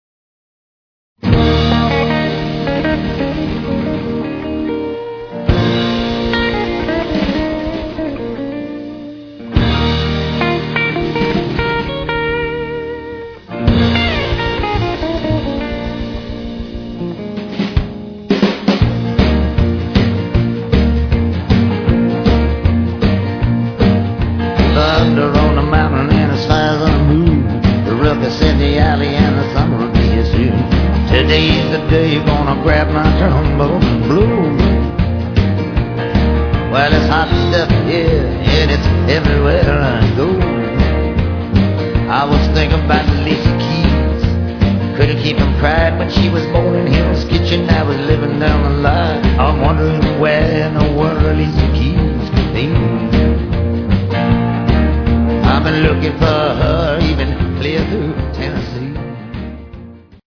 Det svinger stadig rigtig godt hos den gamle herre…